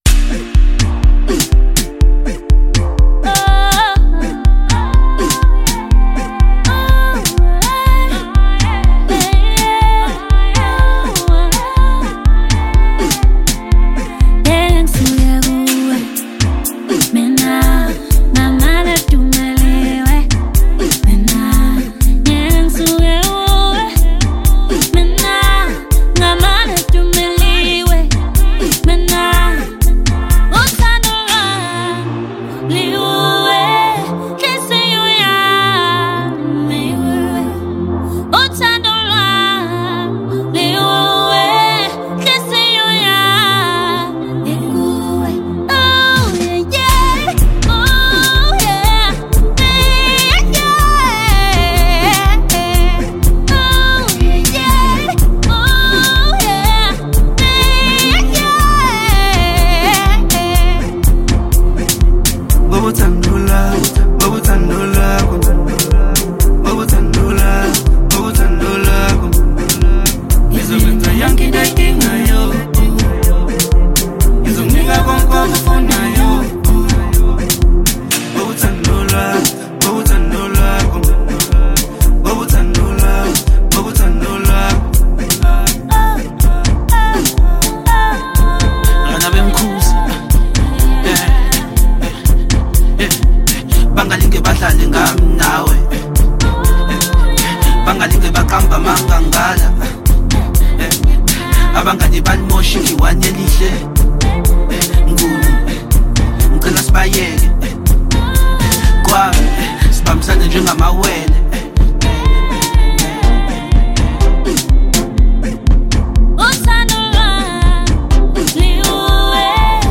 • Genre: Electronic